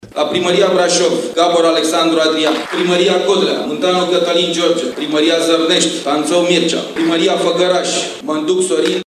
În prezența membrilor și simpatizanților PNL, duminică, a avut loc, la Sala Patria, ceremonia de lansare a celor 58 de candidaţi PNL pentru funcţiile de primar în municipiile, oraşele şi comunele din judeţul Braşov, la alegerile locale din 5 iunie.